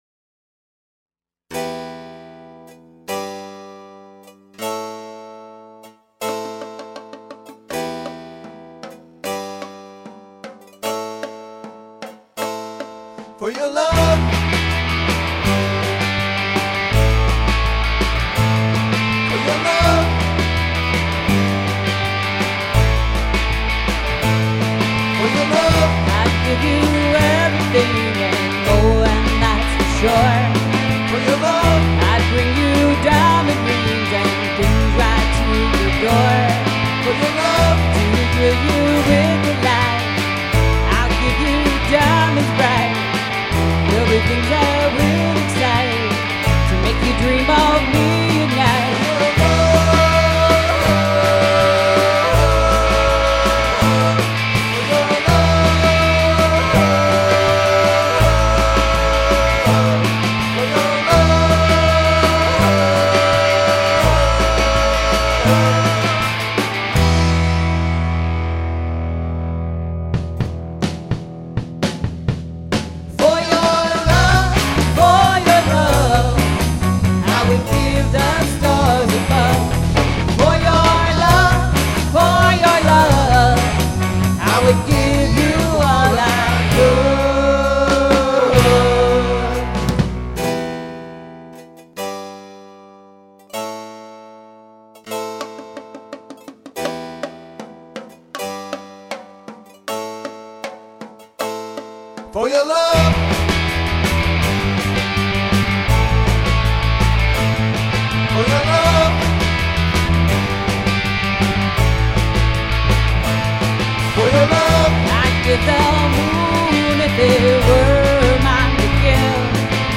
first studio album